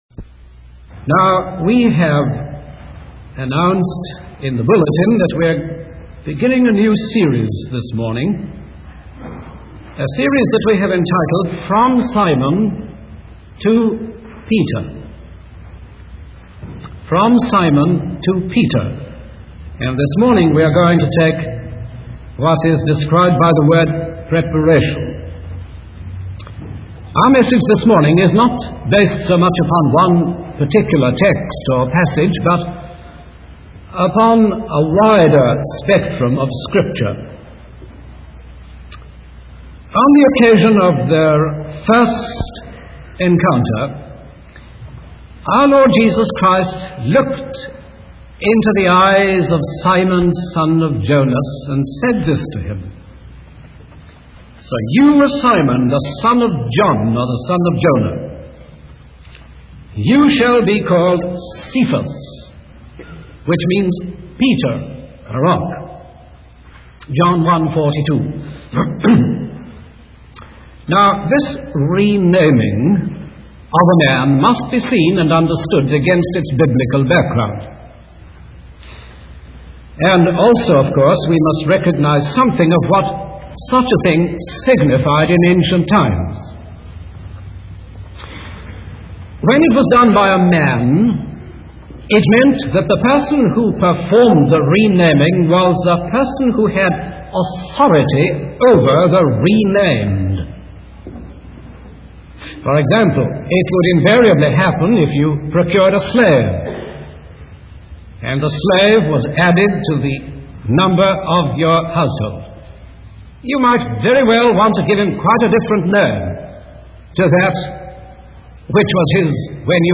In this sermon, the speaker focuses on the transformation of Simon into Peter and the preparation that God undertakes in shaping him. The speaker emphasizes that the kingdom of God requires a radical change and repentance, not just a physical descent or religious affiliation.